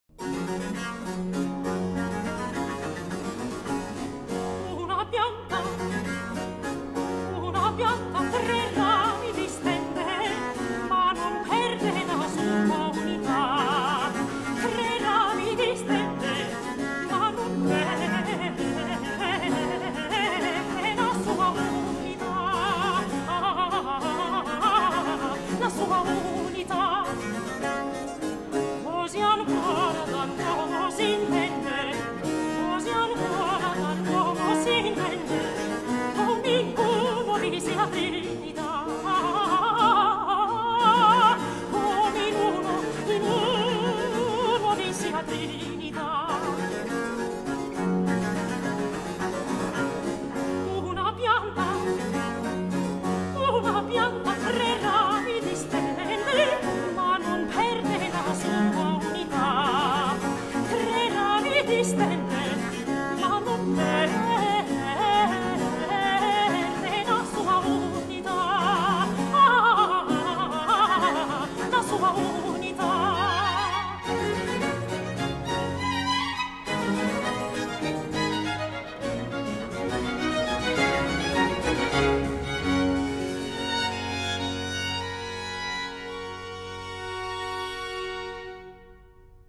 oratorio